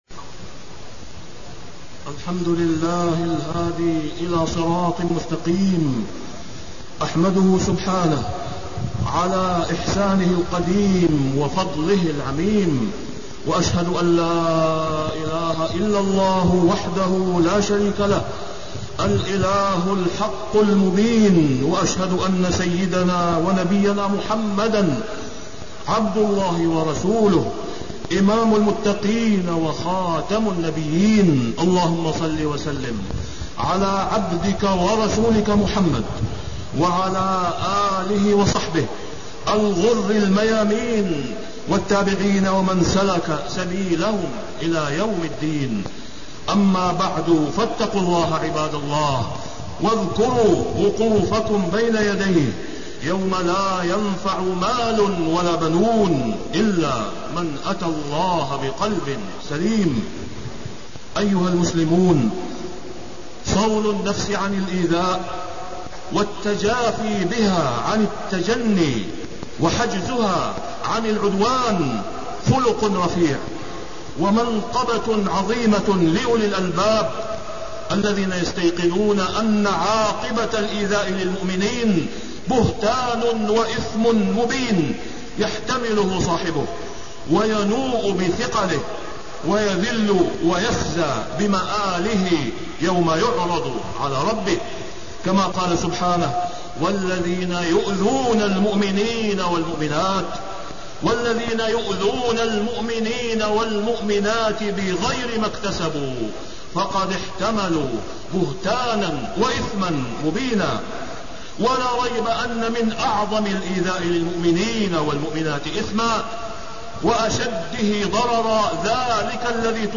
تاريخ النشر ٢٢ شوال ١٤٣١ هـ المكان: المسجد الحرام الشيخ: فضيلة الشيخ د. أسامة بن عبدالله خياط فضيلة الشيخ د. أسامة بن عبدالله خياط فضائل أم المؤمنين عائشة رضي الله عنها